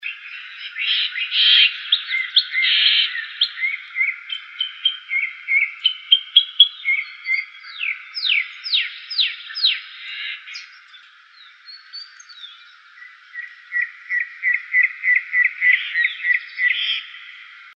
Calandria Grande (Mimus saturninus)
Este individuo estaba realizando,tanto la vocalización como esos saltitos in situ que dan cuando comienzan a cortejar
Nombre en inglés: Chalk-browed Mockingbird
Localidad o área protegida: Reserva Ecológica Costanera Sur (RECS)
Condición: Silvestre
Certeza: Vocalización Grabada